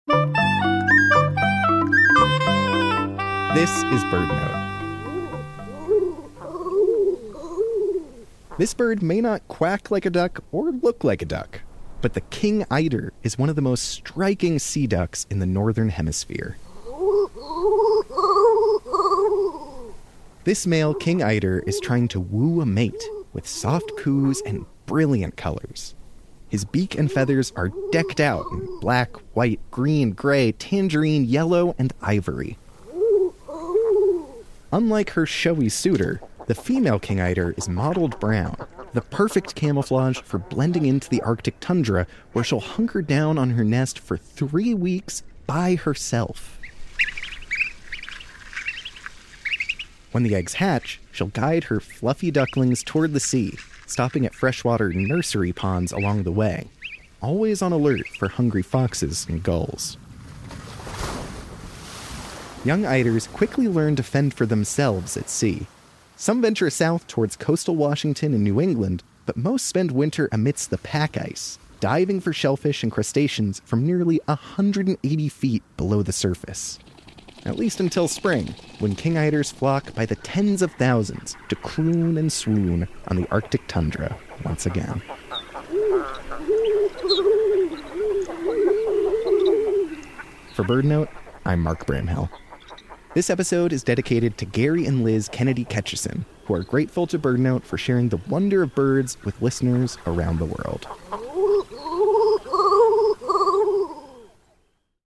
The King Eider is one of the most striking sea ducks in the Northern hemisphere. This male King Eider is trying to woo a mate with soft coos and brilliant colors — his beak and feathers are decked out in black, white, green, grey, tangerine, yellow, a. Podcast links by Plink.